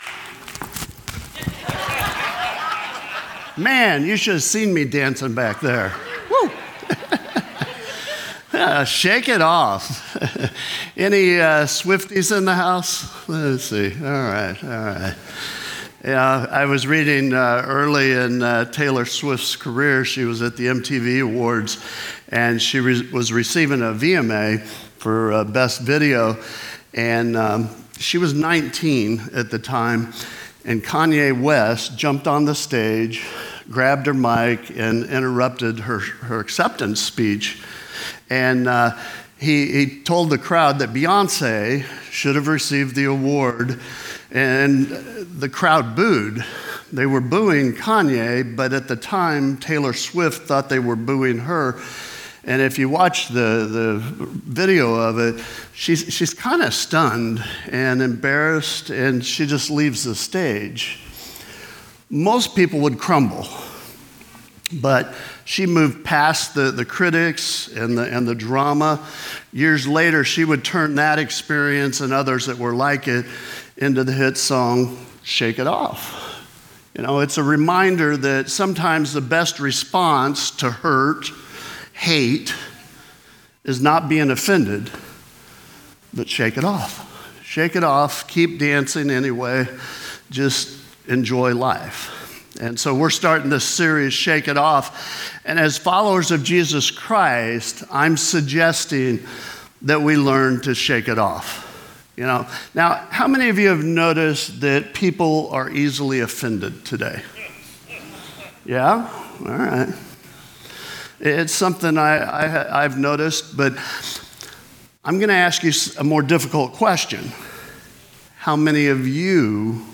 The weekend service is always creative, contemporary and compelling.
The weekend message is highlighted by our cutting-edge worship and multi-media presentations.